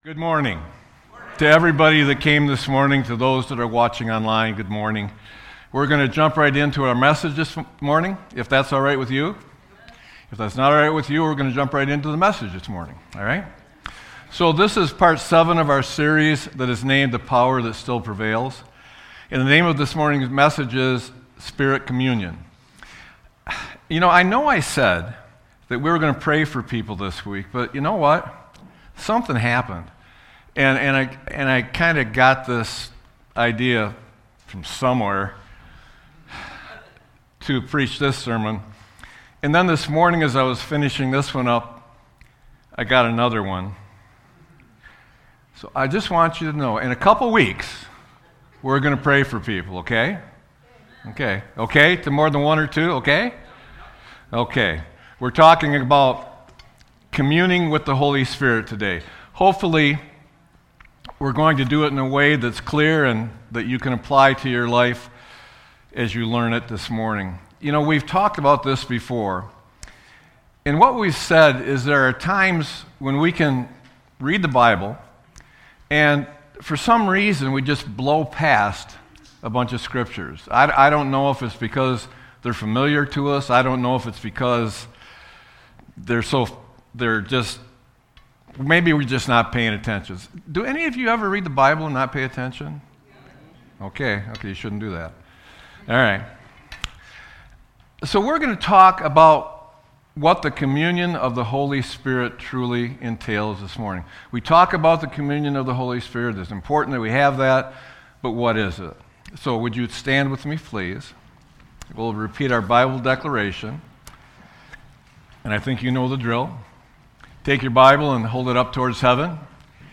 Sermon-3-22-26.mp3